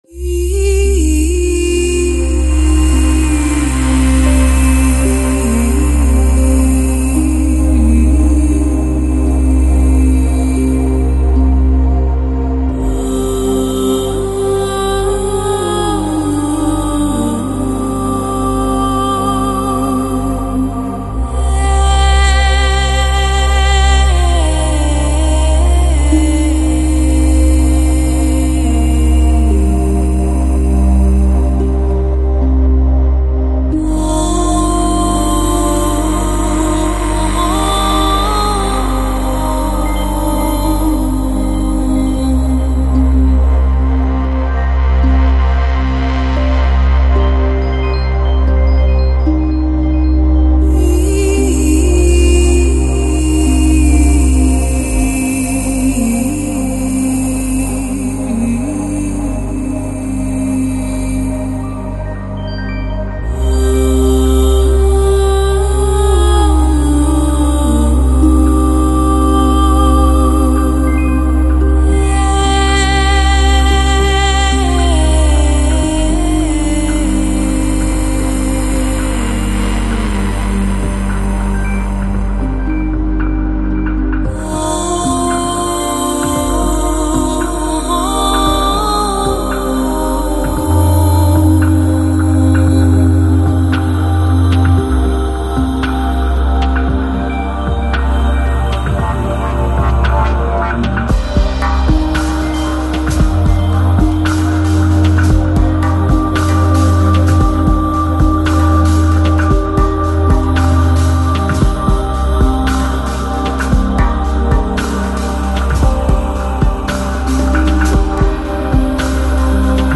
Chillout, Lounge, Ambient, Downtempo, House Год издания